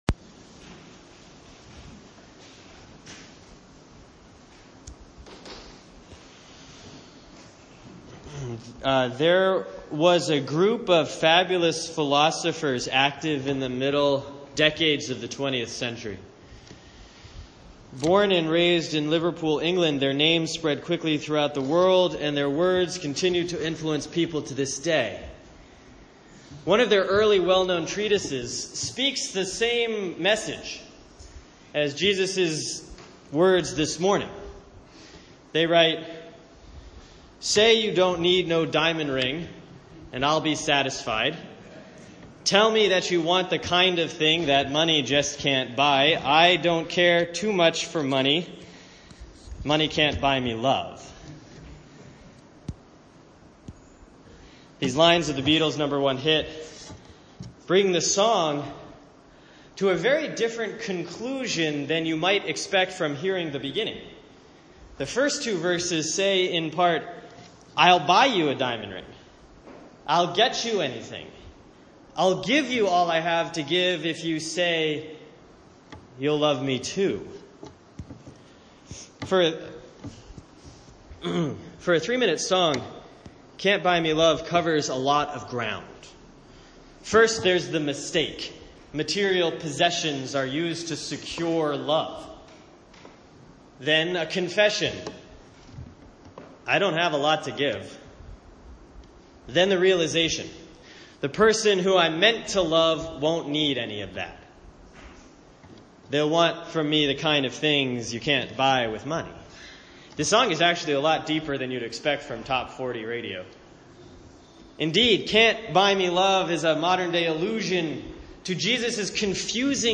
Sermon for Sunday, September 18, 2016 || Proper 20C || Luke 16:1-13